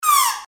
Royalty free sounds: Garden
mf_SE-92-blade_of_grass.mp3